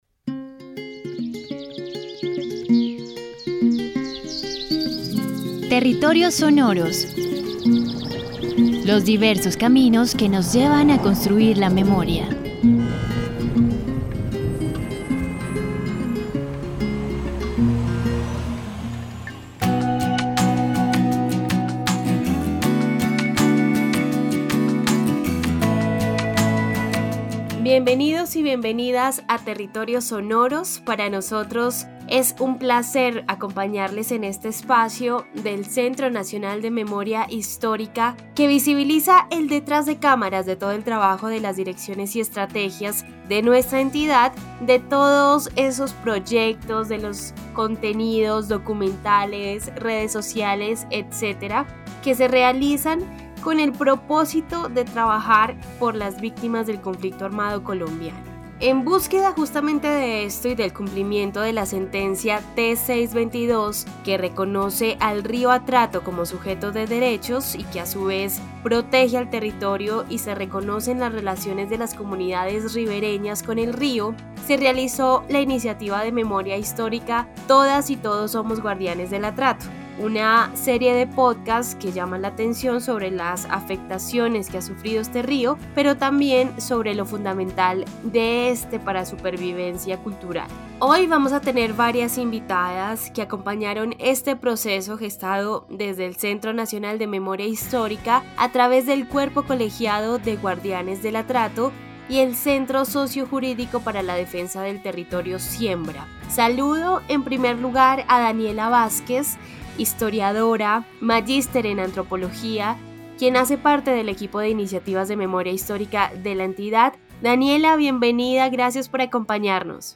Charla sobre los derechos de las comunidades y del Rio Atrato.